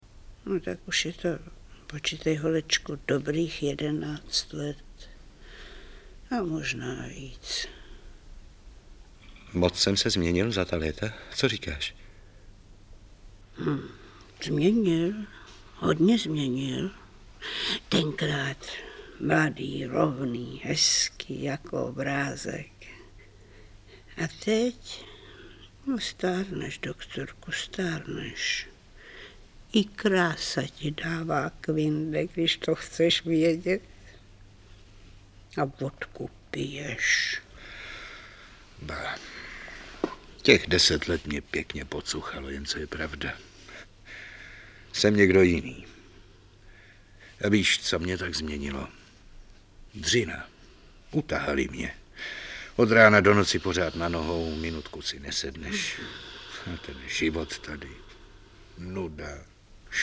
Audiobook
Read: Marie Tomášová